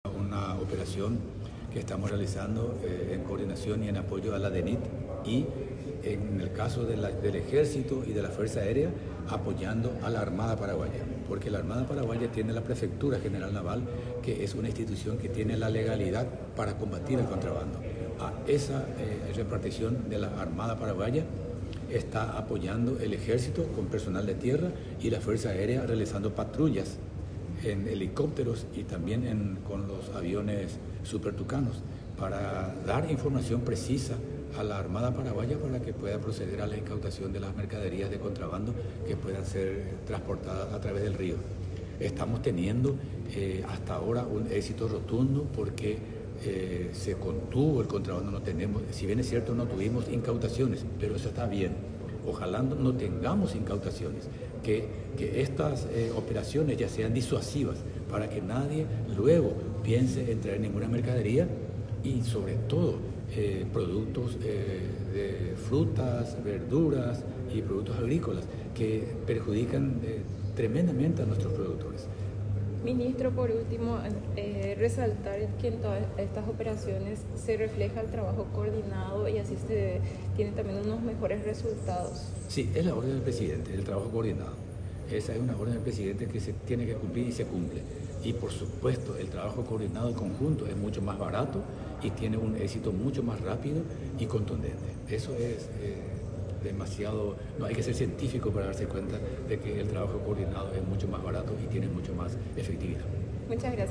El ministro de Defensa Nacional, Oscar González, explicó que la operación que se lleva a cabo en la lucha contra el contrabando es una coordinación entre la DNIT, el Ejército y la Fuerza Aérea, en apoyo a la Armada Paraguaya.
El entrevistado subrayó que la operación está siendo un «éxito rotundo» porque ha logrado contener el contrabando.